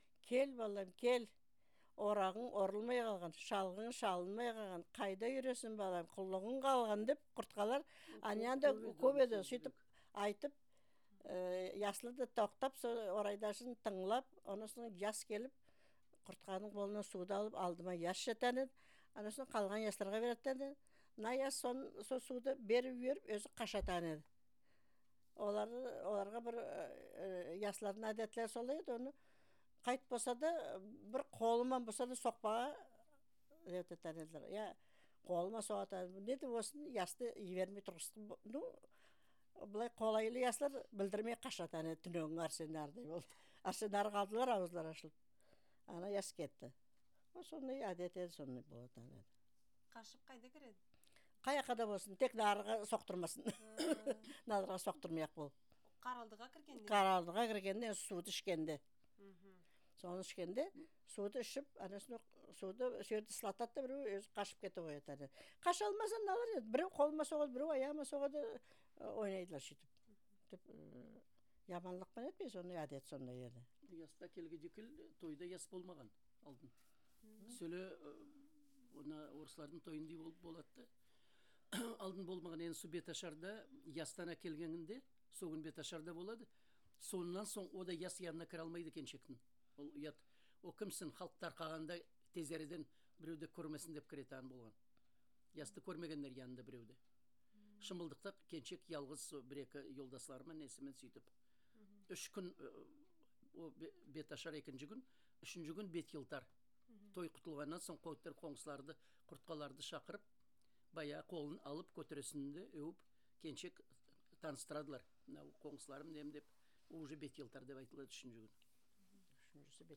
Интервью
Село Канглы Минераловодского р-на Ставропольского края